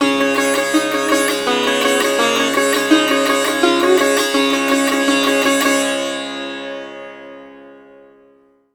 SITAR GRV 01.wav